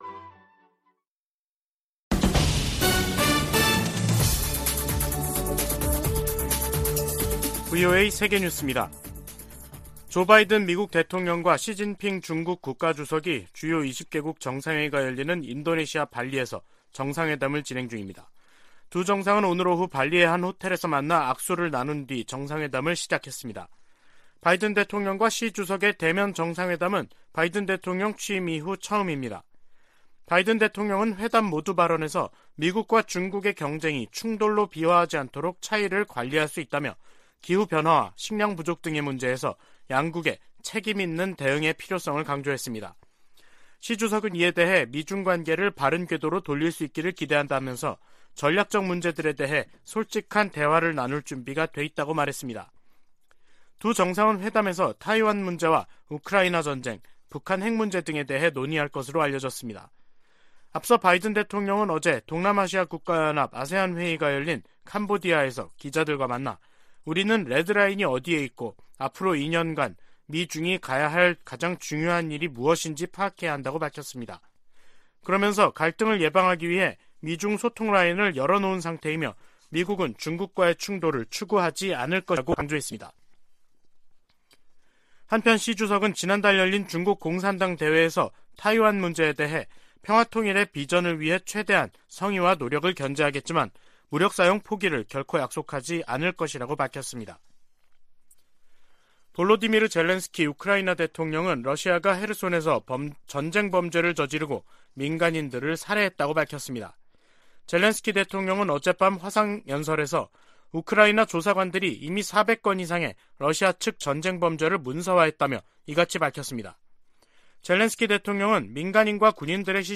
VOA 한국어 간판 뉴스 프로그램 '뉴스 투데이', 2022년 11월 14일 3부 방송입니다. 미국과 한국, 일본 정상들이 미한일 정상이 13일 캄보디아에서 만나 북한 문제와 관련해 억제력을 강화하기 위해 협력하기로 합의했습니다. 미,한,일 정상의 ‘프놈펜 공동성명’은 북한의 고조된 핵 위협에 대응한 세 나라의 강력한 공조 의지를 확인했다는 평가가 나오고 있습니다.